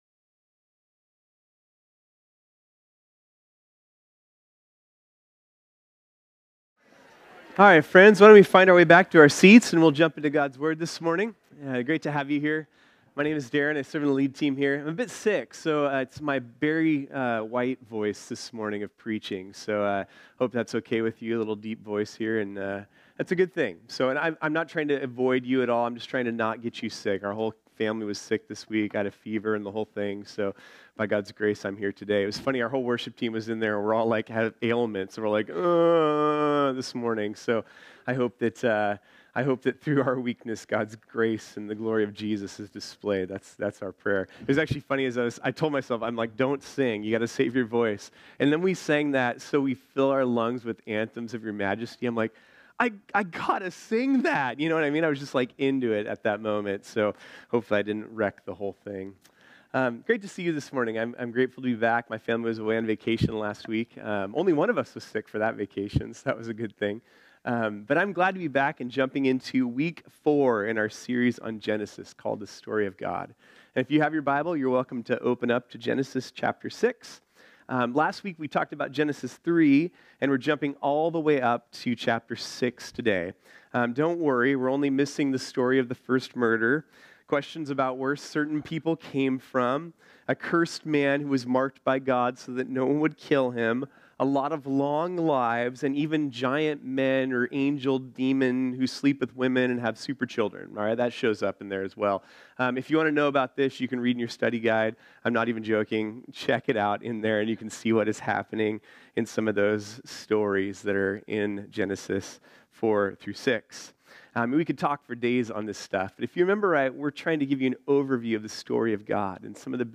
This sermon was originally preached on Sunday, January 27, 2019.